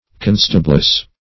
Constabless \Con"sta*bless\, n. The wife of a constable.